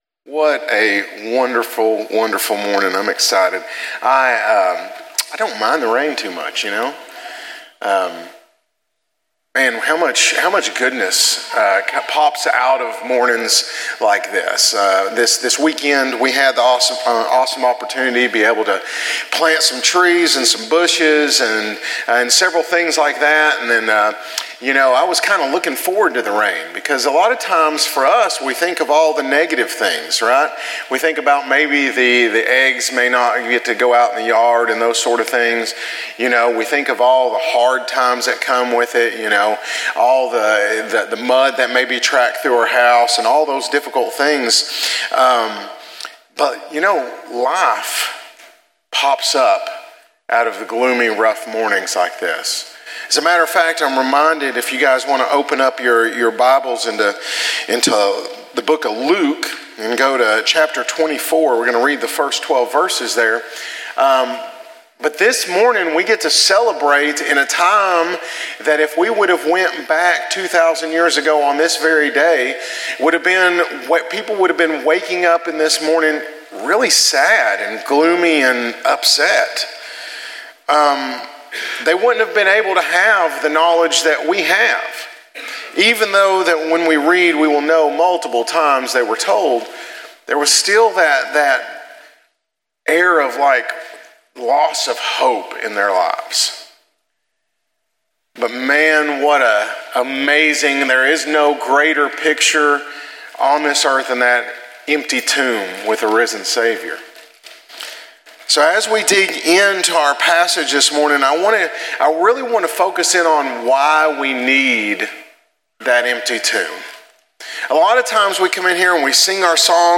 The Garden and the Grave - Easter Sunrise Service | Fireside Fellowship